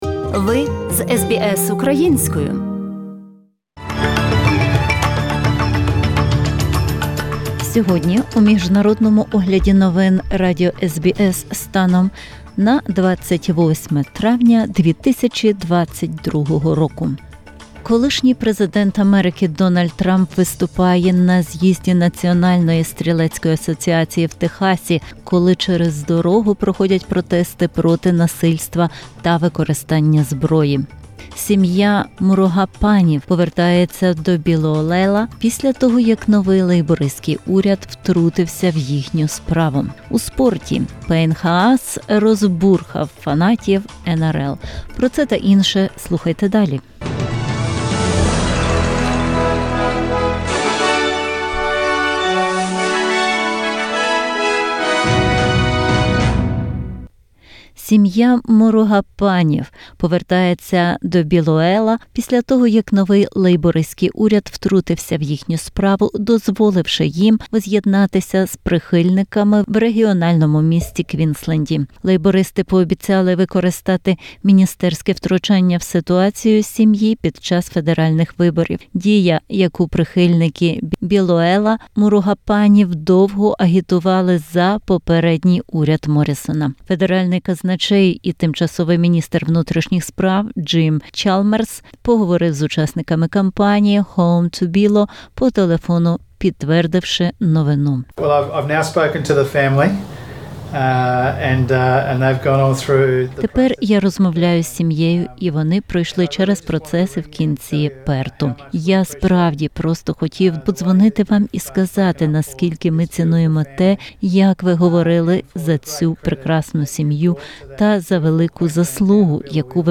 SBS Ukrainian, 3 pm FM, TV Ch. 38 and 302, every Thursday Source: SBS